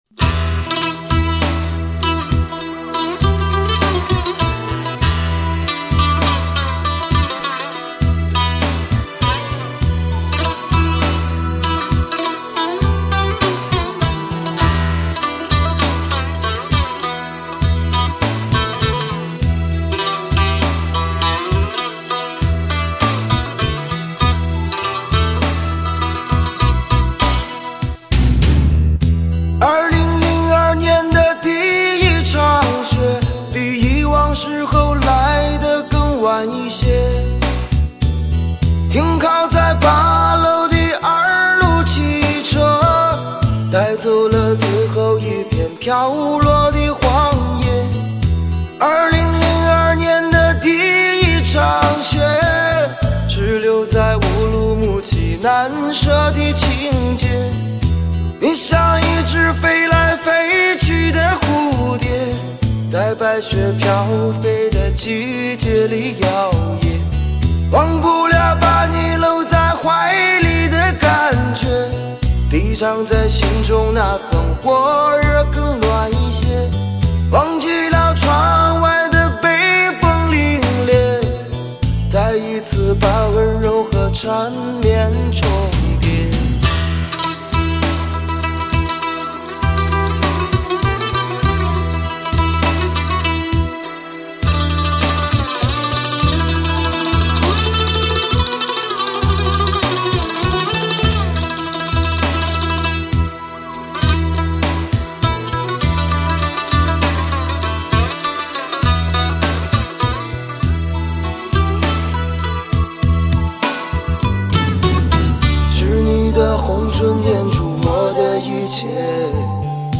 弹拨尔